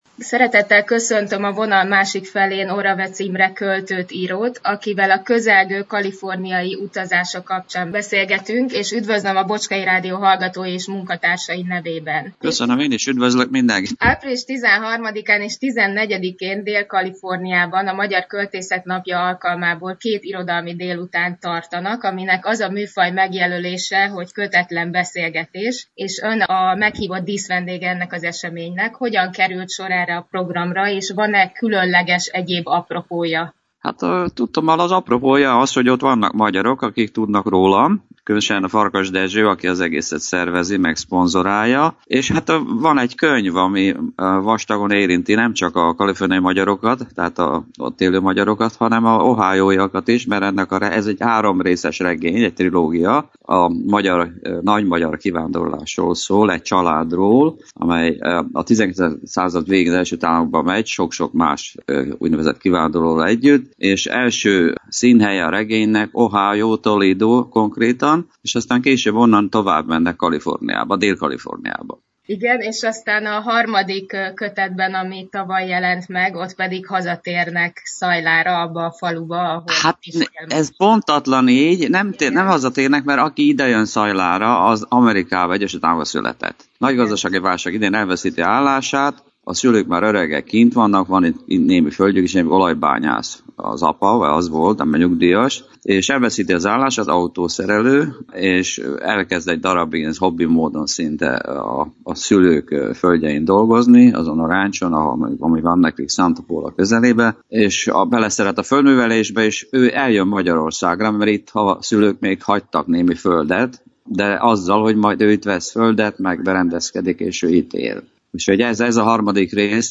A beszélgetés rövidített változatát a március 31-i adásunkban hallhatták, a teljes anyagot itt tesszük közzé.